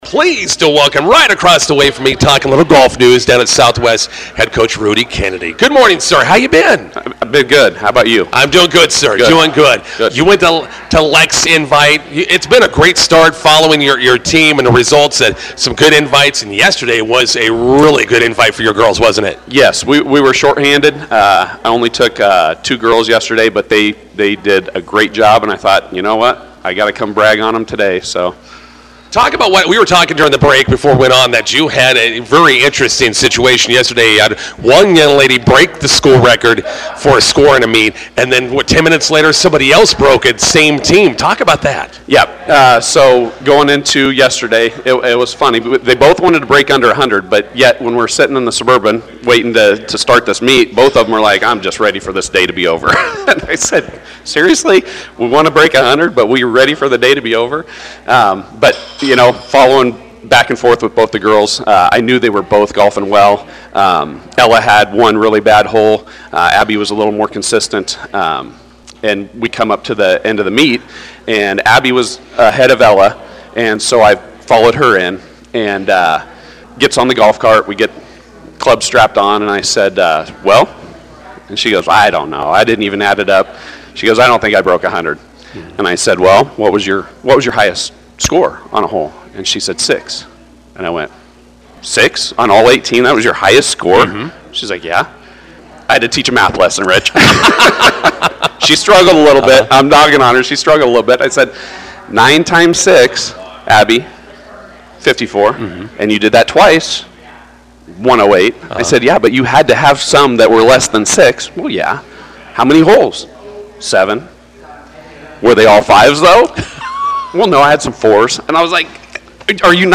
INTERVIEW: Two Southwest HS golfers break school record in same meet.